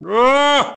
File:K. Rool (evil laugh) 1.oga
Line of King K. Rool in Donkey Kong 64.
K._Rool_(evil_laugh)_1.oga.mp3